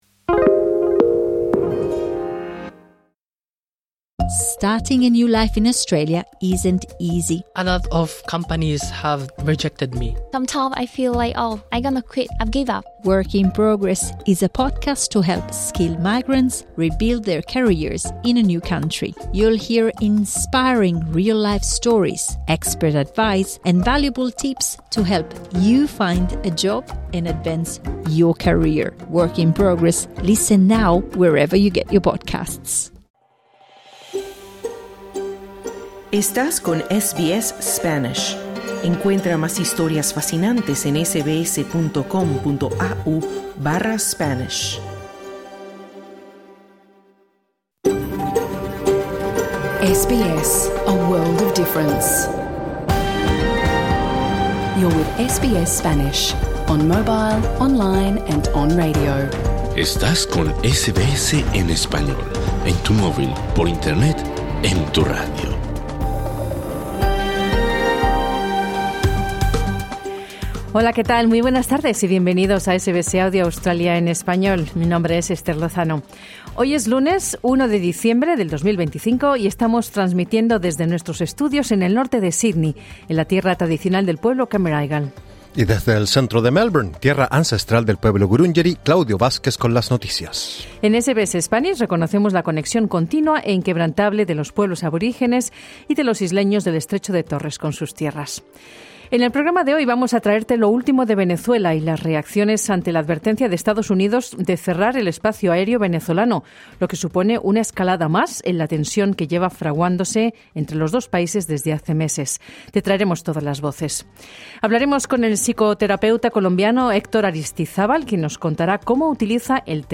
Programa en Vivo | SBS Spanish | 1 diciembre 2025 Credit: Getty Images